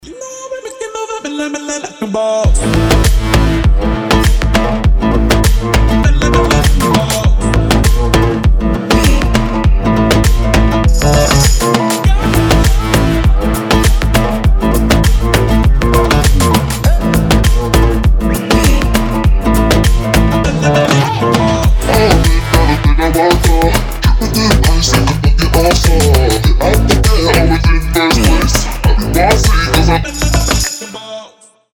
deep house
басы
восточные
этнические
Модный дип с этническими мотивами